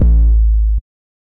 The Mafia 808.wav